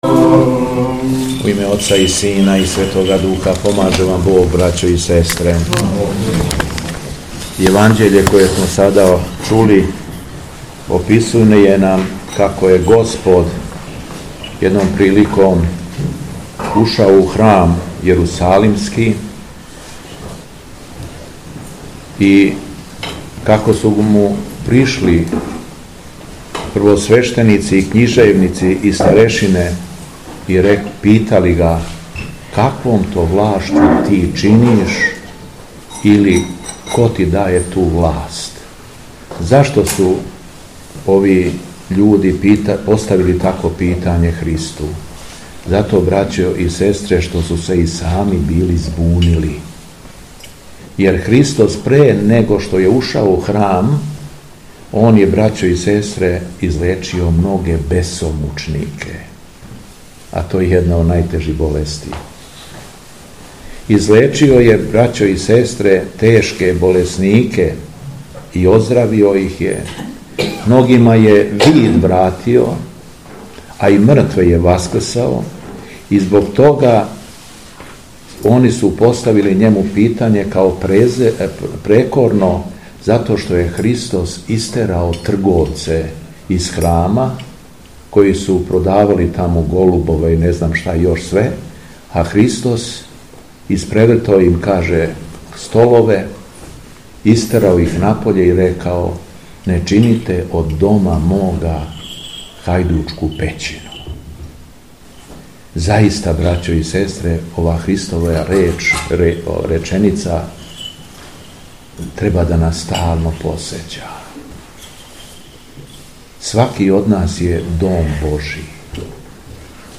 СВЕТА АРХИЈЕРЕЈСКА ЛИТУРГИЈА У МАНАСТИРУ РАЛЕТИНЦУ
Беседа Његовог Високопреосвештенства Митрополита шумадијског г. Јована